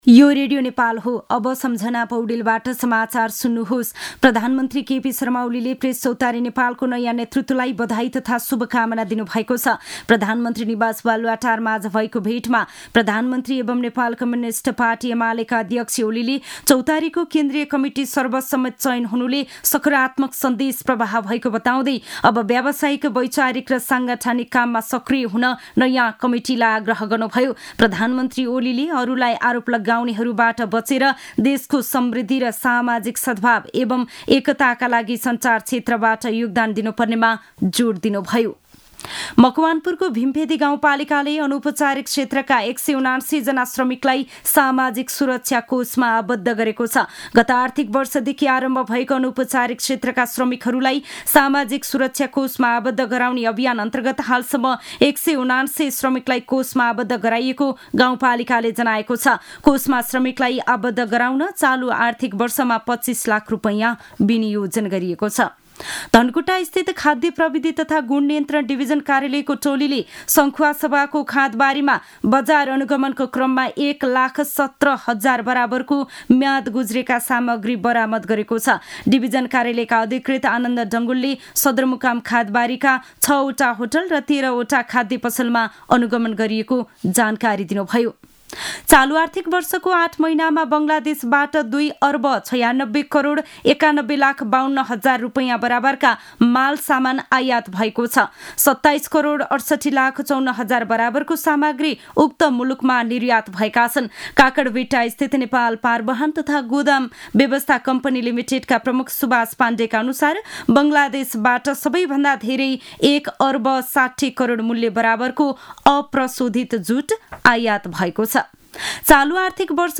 An online outlet of Nepal's national radio broadcaster
मध्यान्ह १२ बजेको नेपाली समाचार : १४ चैत , २०८१